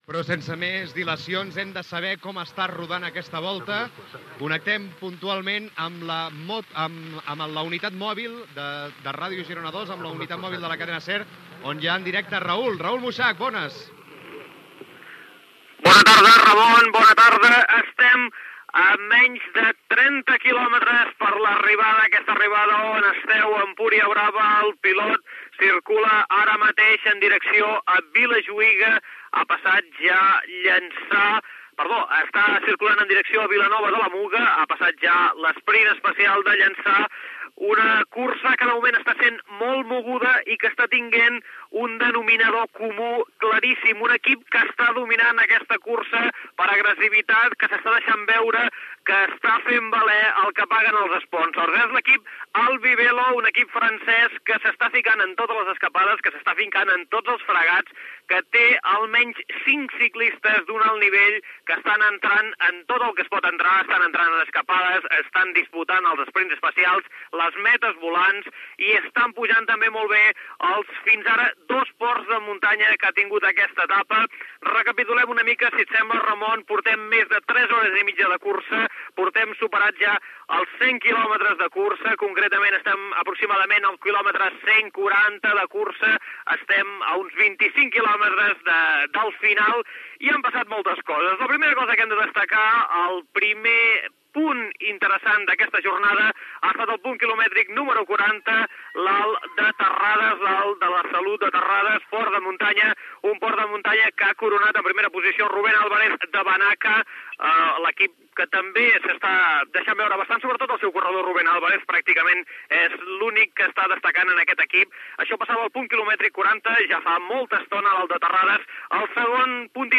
Transmissió d'una etapa de la Volta Ciclista a l'Empordà, a 25 quilòmetre de l'arribada a Empúria Brava Gènere radiofònic Esportiu